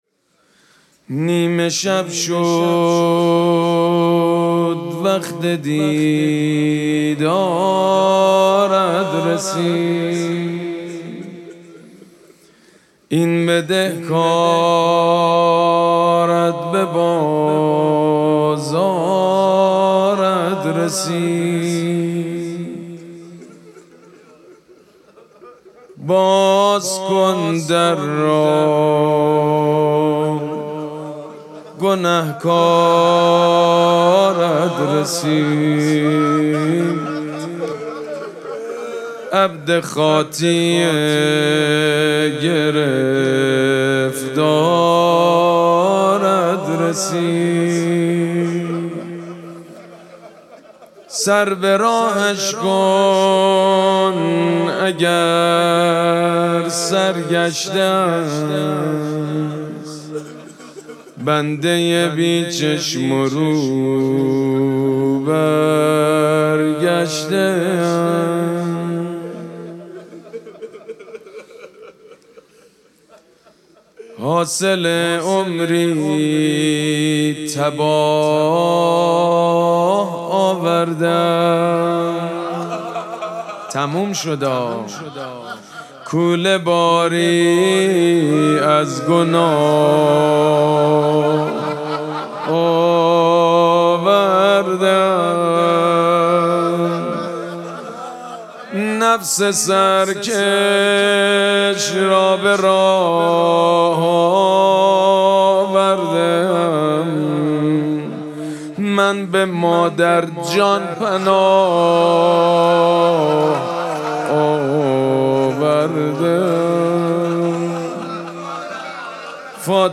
مراسم مناجات شب بیست و سوم ماه مبارک رمضان یکشنبه ۳ فروردین ماه ۱۴۰۴ | ۲۲ رمضان ۱۴۴۶ حسینیه ریحانه الحسین سلام الله علیها
سبک اثــر مناجات مداح حاج سید مجید بنی فاطمه